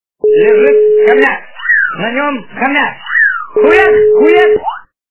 » Звуки » Люди фразы » Голос Жириновского - лежит хомяк
При прослушивании Голос Жириновского - лежит хомяк качество понижено и присутствуют гудки.